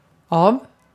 Ääntäminen
IPA : /ɒv/ IPA : /ʌv/ IPA : /əv/